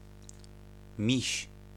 Close front unrounded vowel
[ˈmʲiɕ] 'teddy bear' See Polish phonology